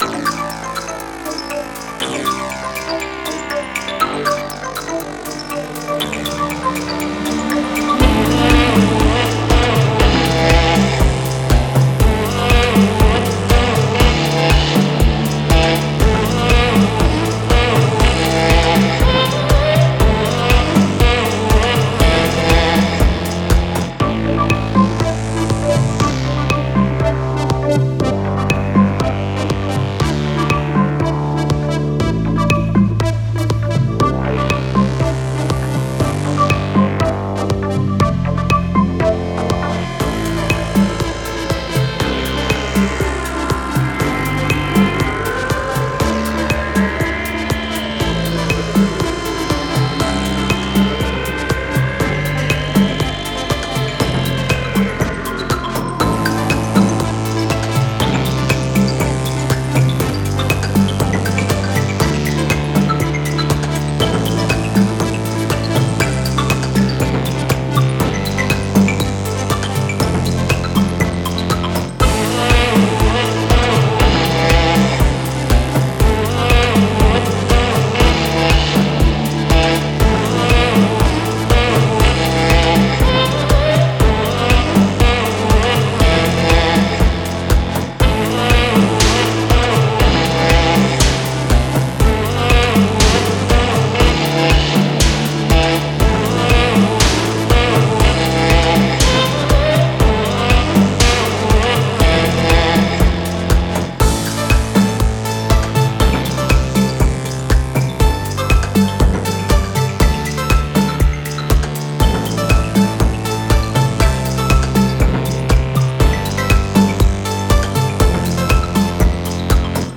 Not mixed
Rough mix